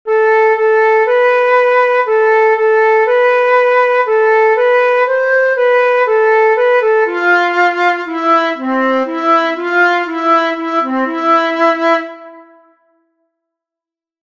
Japanese music